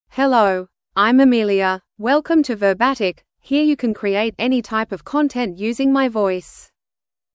FemaleEnglish (Australia)
Amelia is a female AI voice for English (Australia).
Voice sample
Female
English (Australia)